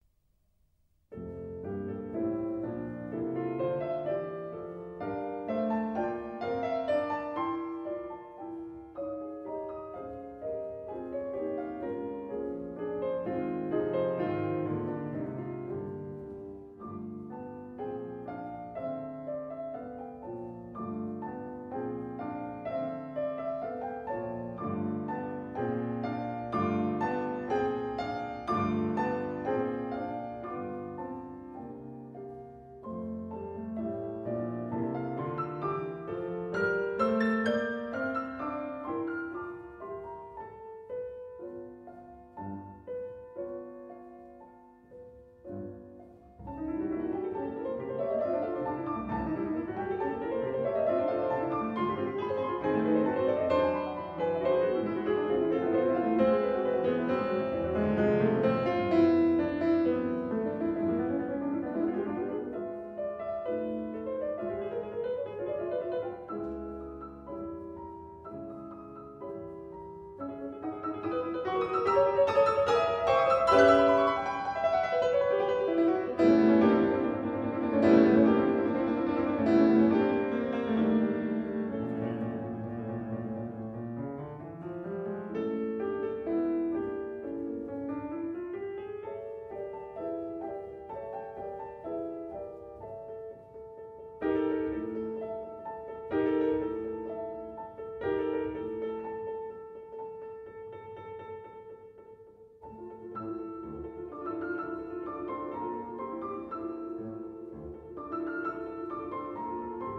String Quartet in F major
Allegro moderato – très doux